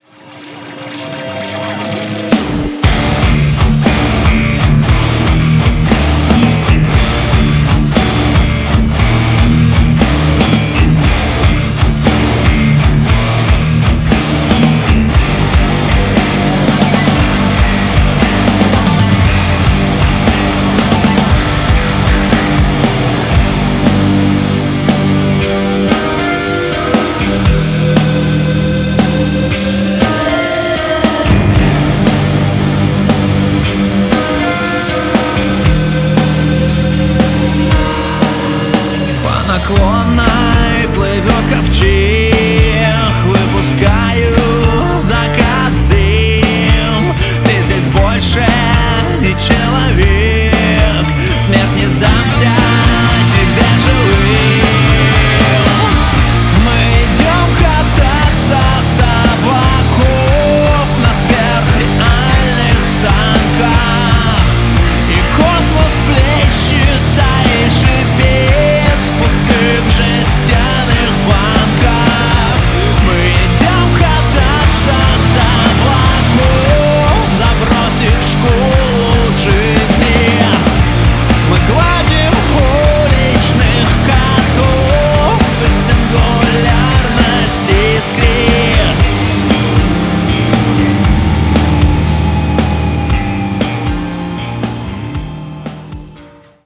Песня.Рок.